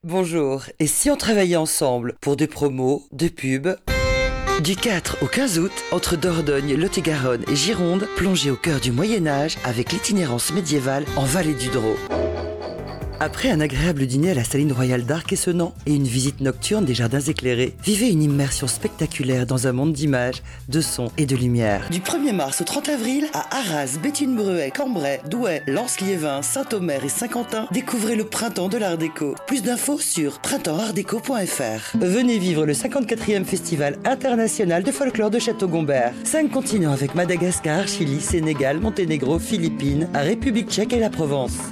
La voix, la voix qui prend peu de rides mais garde sa chaleur son empathie son enthousiasme !
Sprechprobe: Sonstiges (Muttersprache):
doc et téléphone.mp3